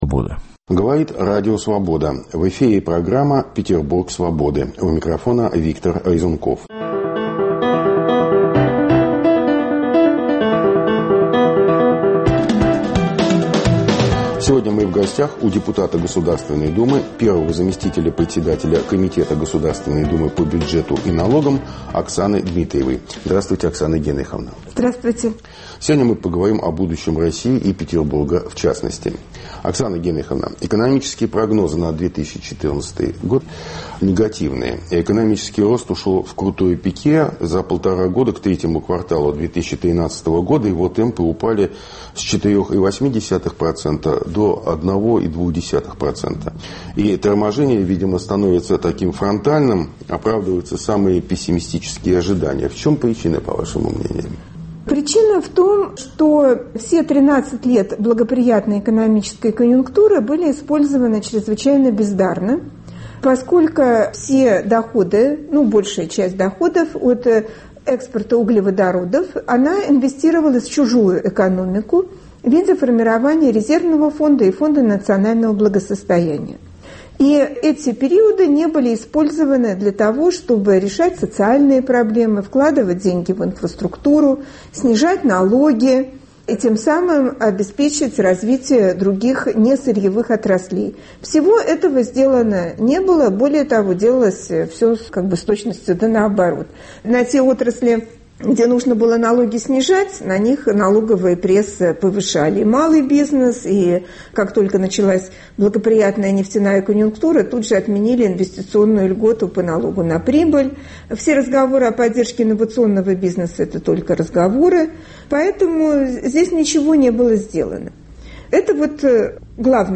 Губернатор Георгий Полтавченко и Смольный - где стратегия? Обо всем этом беседа с депутатом Госдумы Оксаной Дмитриевой